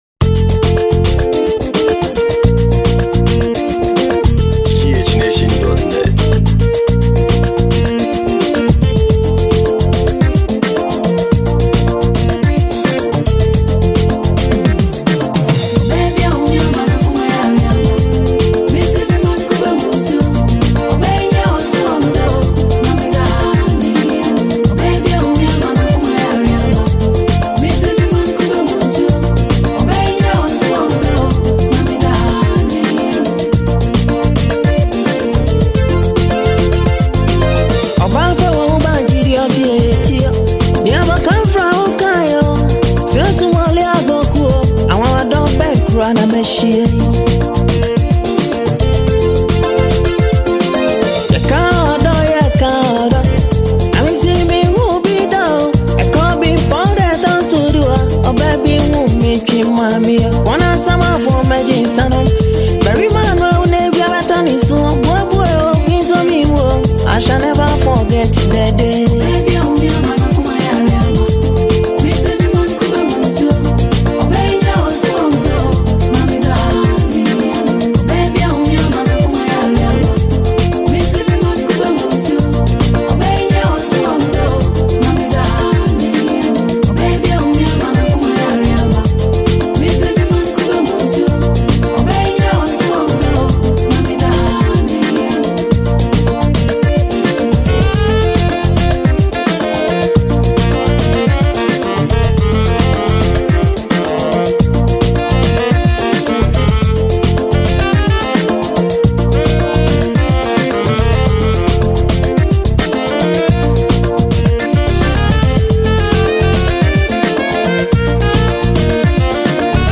a traditional song